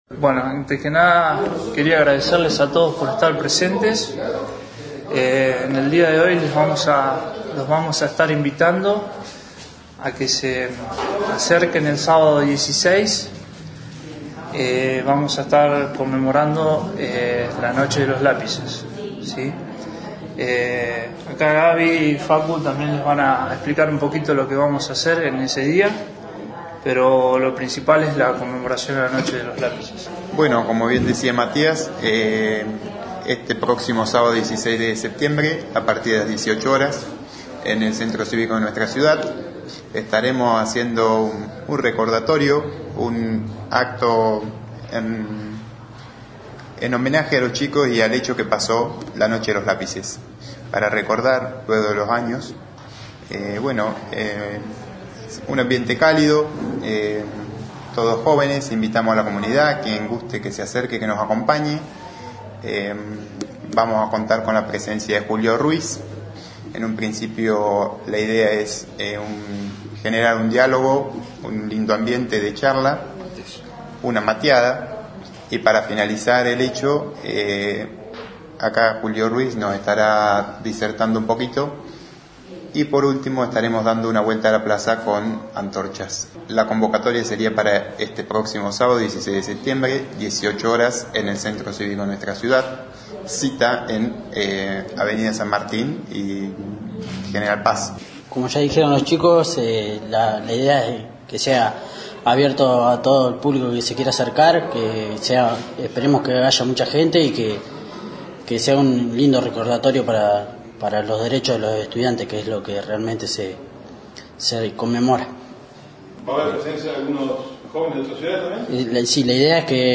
Conferencia de Prensa - Juventud Cambiemos